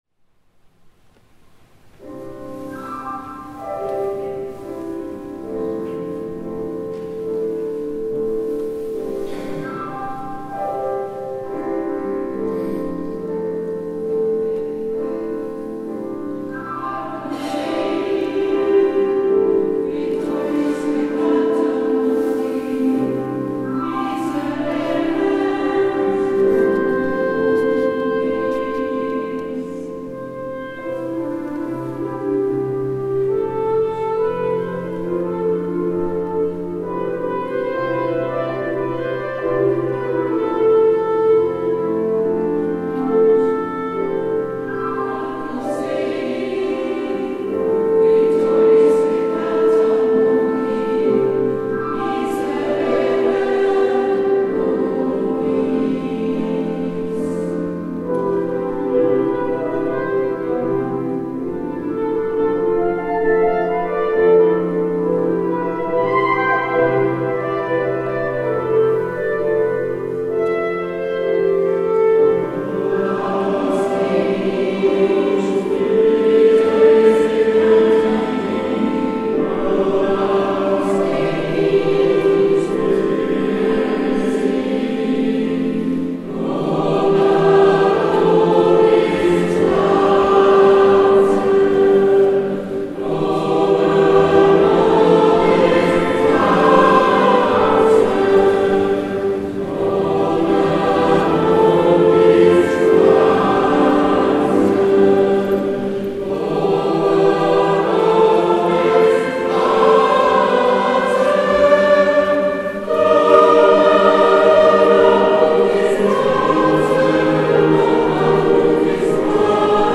Gattung: Messe
Besetzung: Chor SATB, Klarinette, Klavier